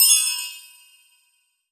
chimes_magic_bell_ding_3.wav